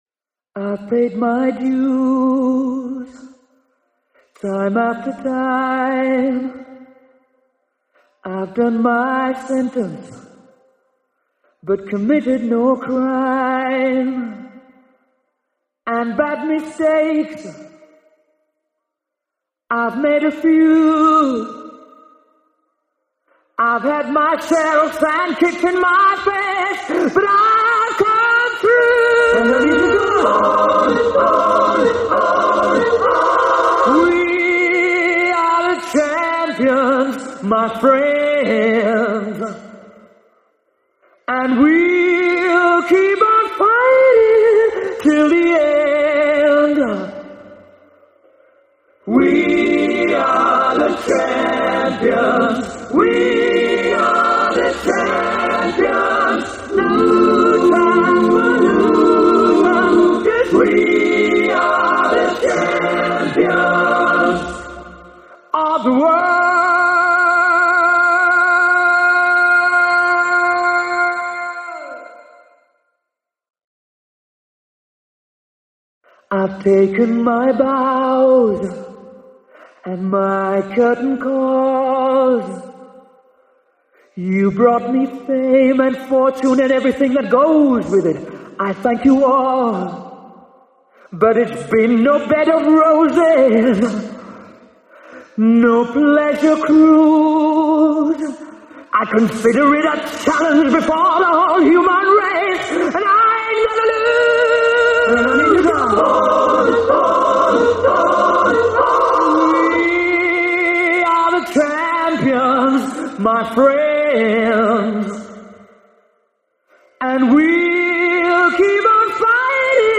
Главная » Файлы » Акапеллы » Скачать Зарубежные акапеллы